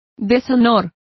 Complete with pronunciation of the translation of dishonour.